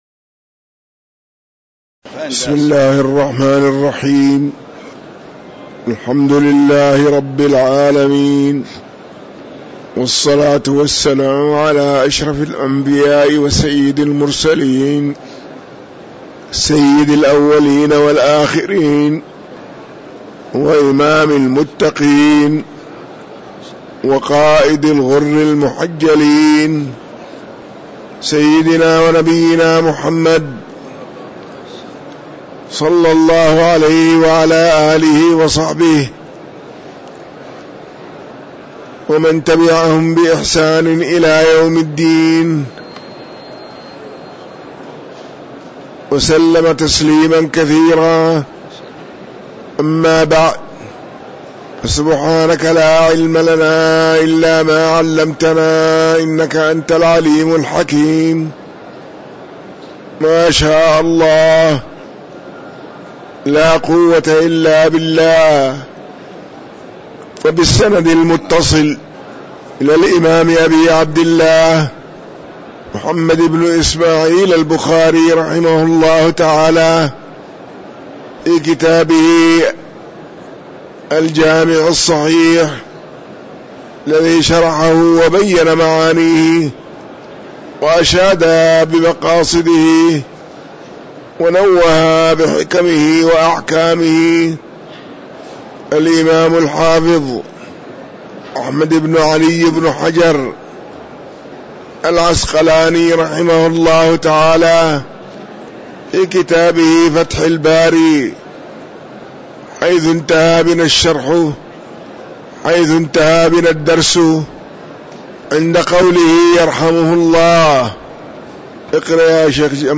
تاريخ النشر ١٧ جمادى الآخرة ١٤٤٤ هـ المكان: المسجد النبوي الشيخ